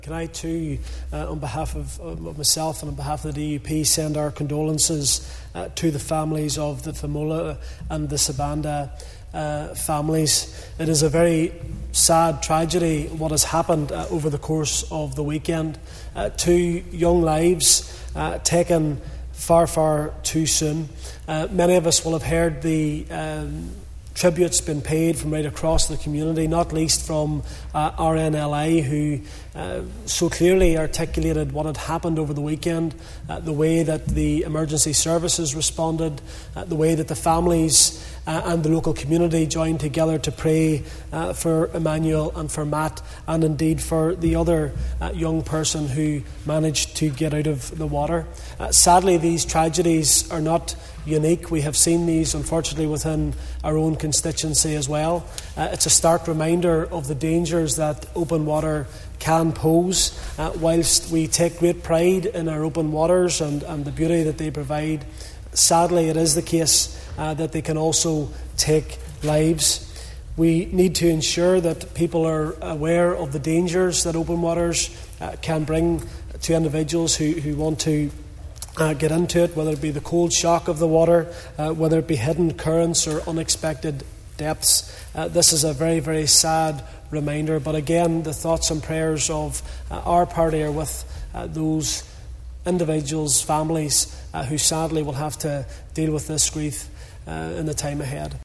DUP MLA Gary Middleton echoed those sentiments………..